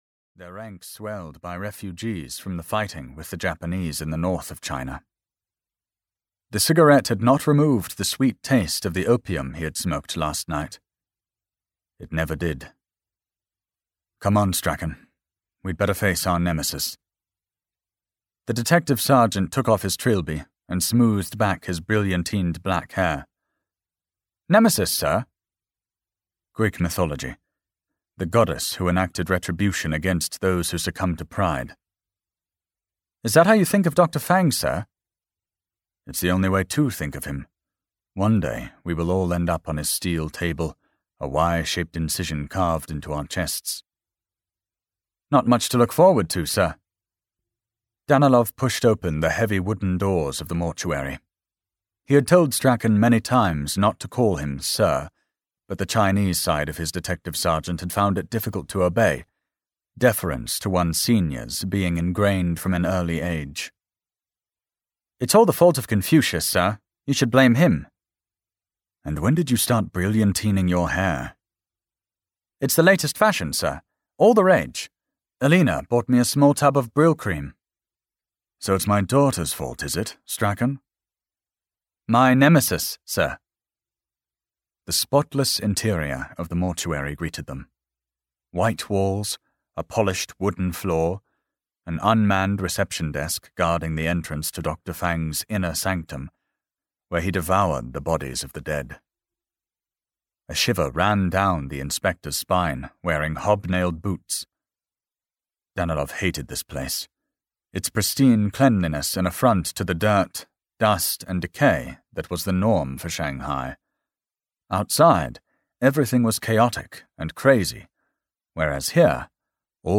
The Killing Time (EN) audiokniha
Ukázka z knihy